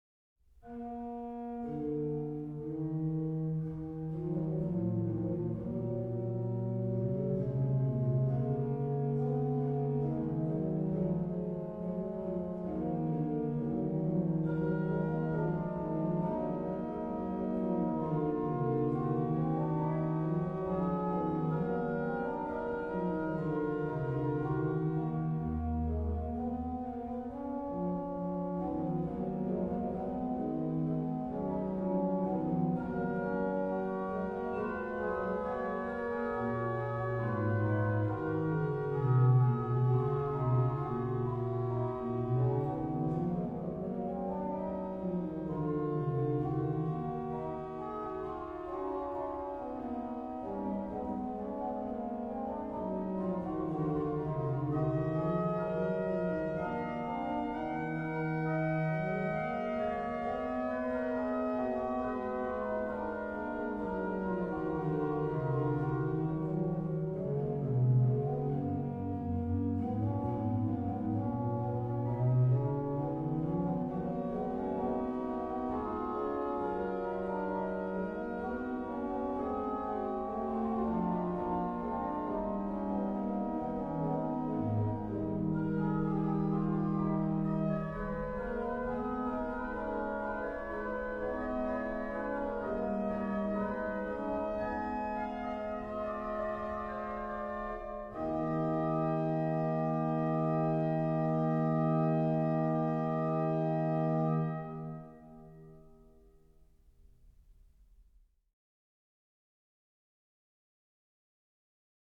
Venue   1736 Erasmus Bielfeldt organ, St. Wilhadi, Stade, Germany
Registration   Pos: Oct8, Qnt8